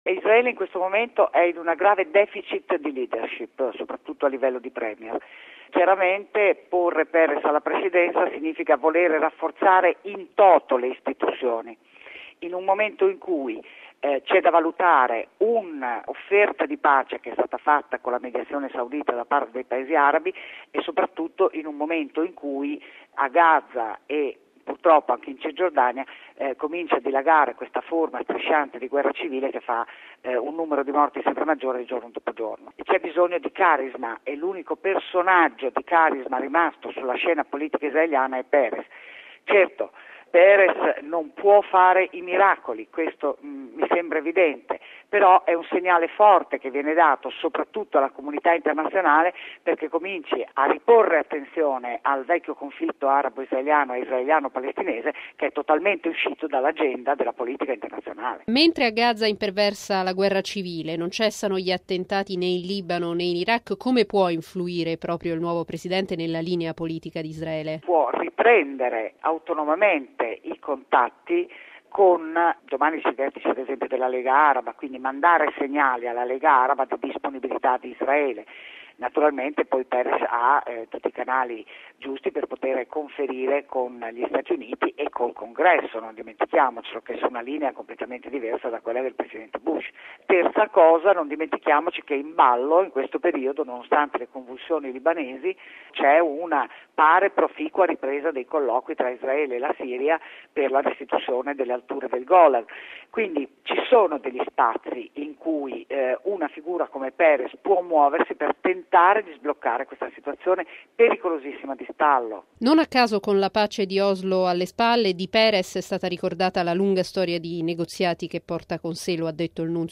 ne ha parlato con la professoressa